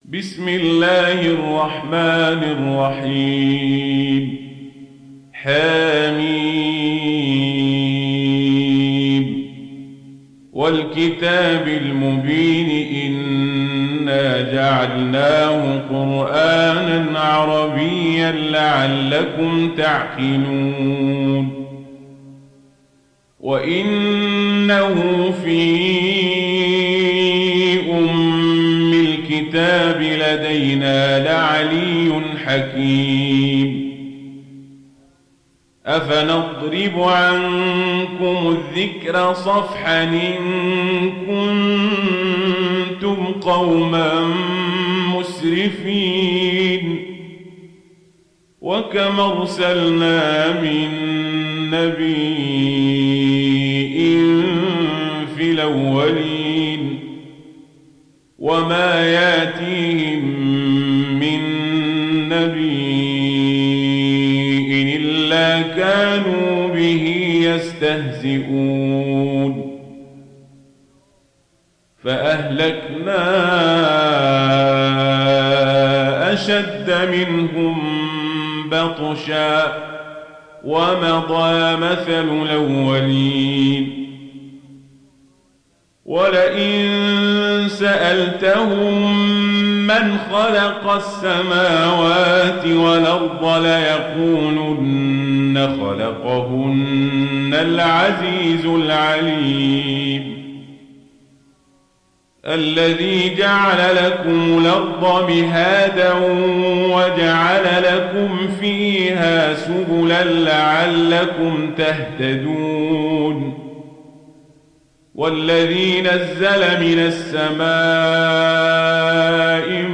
Warsh an Nafi